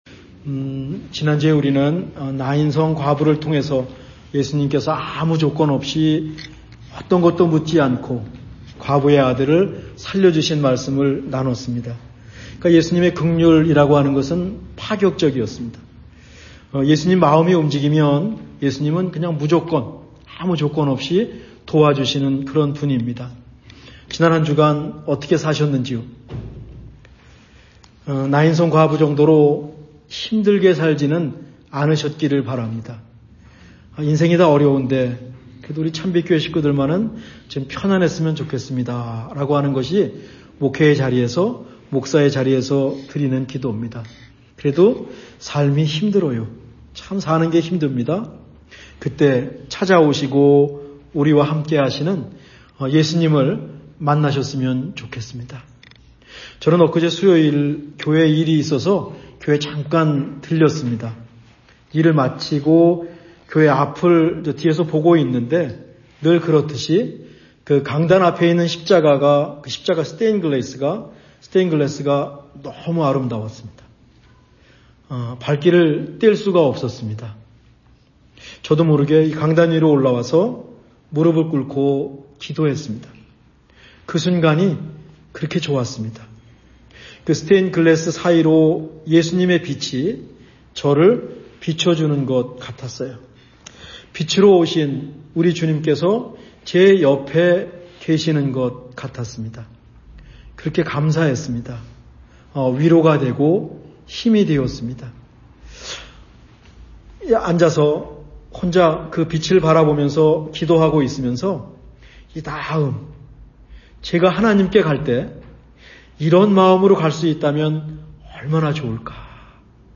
2025년 10월 4주 말씀